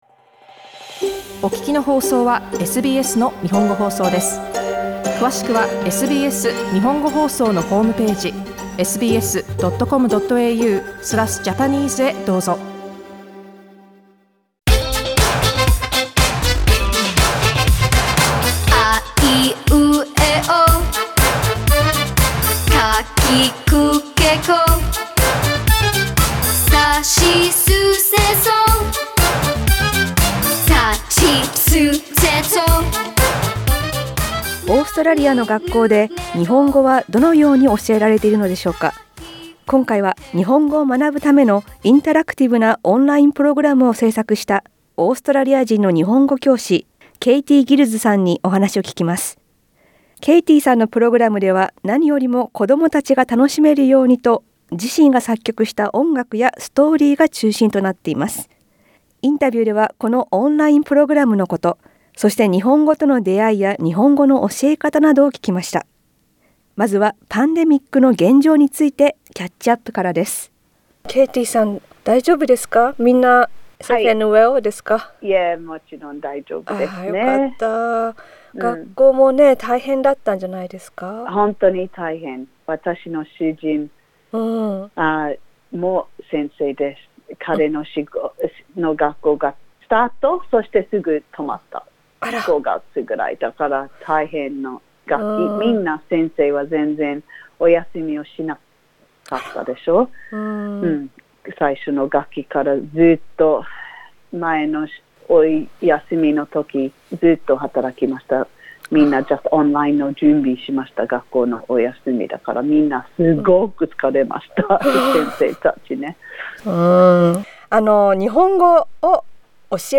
インタビューでは、このオンラインプログラムについてや、日本語との出会い、日本語教師としてのこれまでの経験などを聞きました。